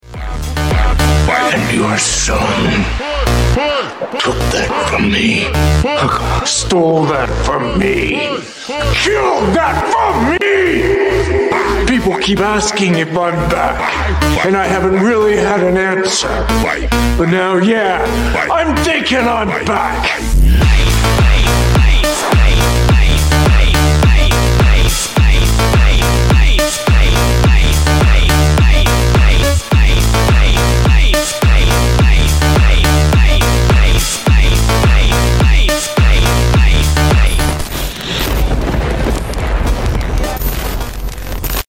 SUPER SLOWED REVERB BRAZILIAN PHONK